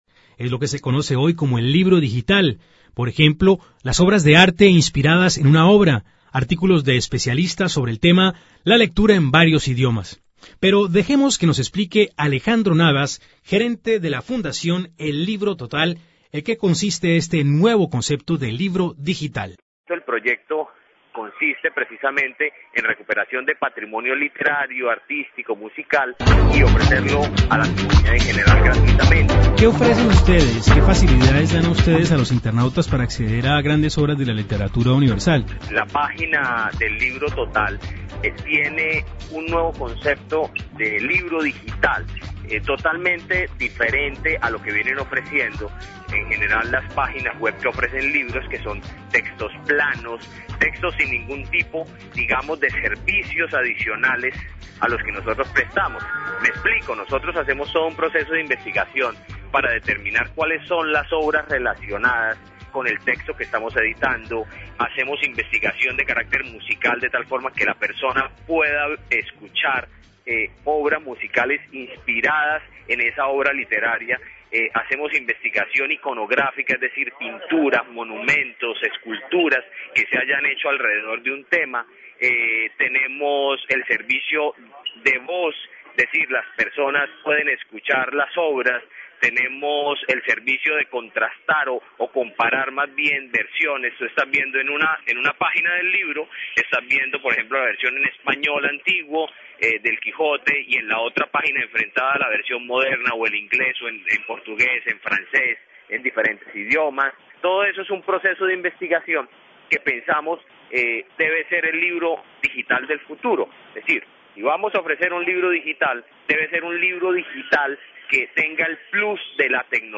La fundación el Libro Total pone a disposición del público de forma gratuita libros digitales que permiten la lectura en varios idiomas y complementa los libros con artículos de especialistas, entre otros servicios. El proyecto incluye la recuperación de patrimonios literarios, artísticos y musicales. Escuche el informe de Radio Francia Internacional.